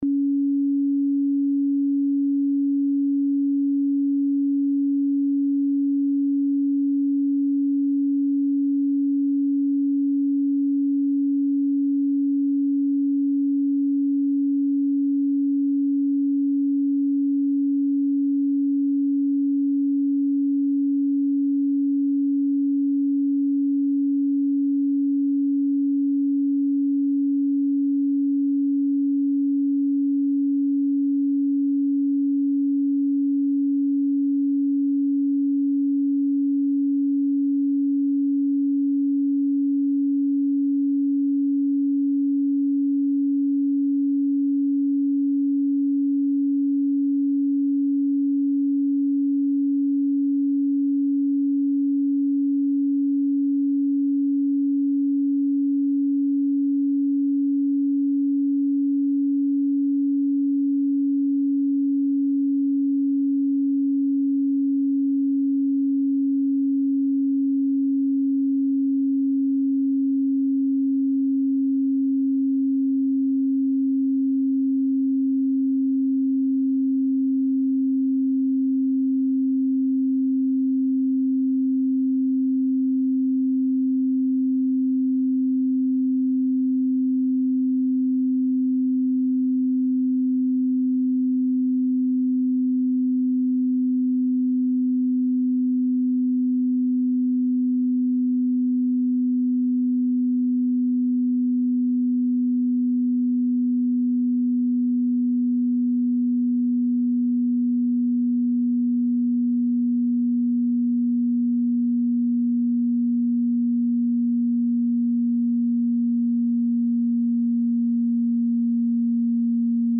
Right Descending - sample - this is the first 5 minutes of the right channel of Beach Meds and can be used with Left Descending and your choice of other ambient sounds.